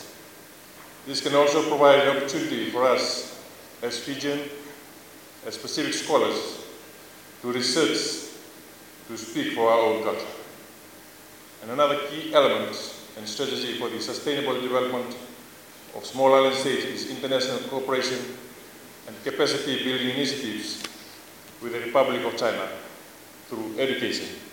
Minister for Education Aseri Radrodro made the remarks while addressing attendees at the Forum on Sustainable Development of Small Island States.